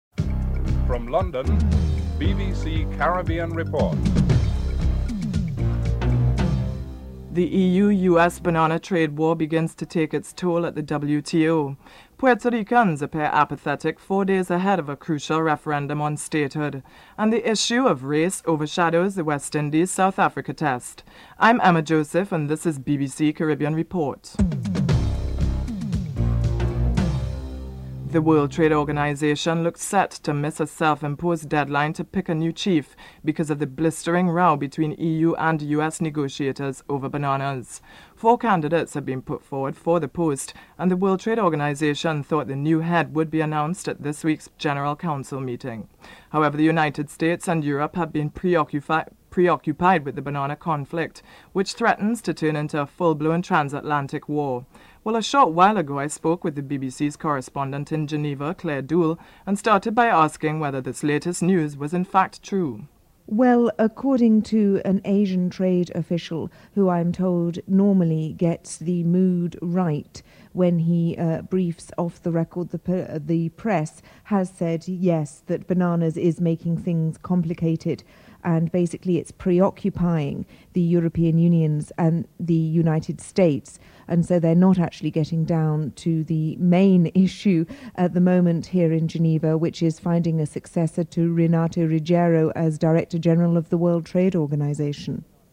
Nicaraguan Ambassador to Washington is interviewed (08:43-10:56)